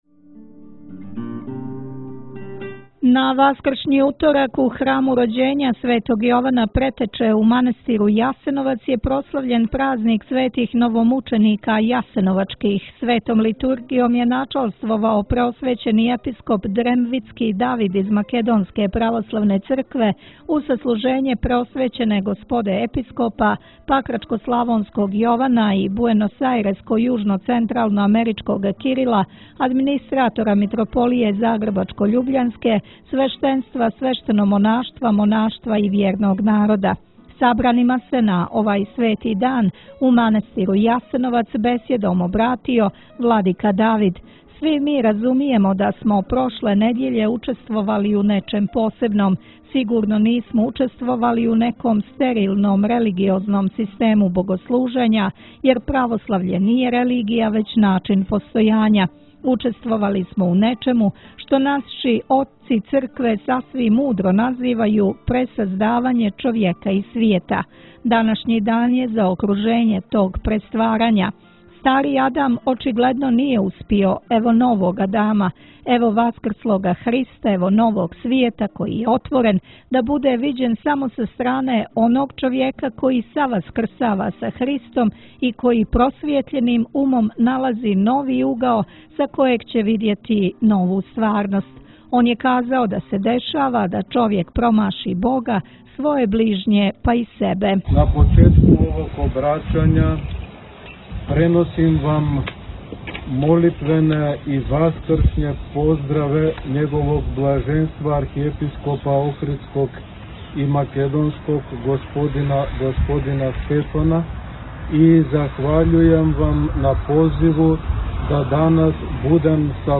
Сабранима на овај свети дан у манастиру Јасеновац бесједом се обратио Епископ дремвитски Давид.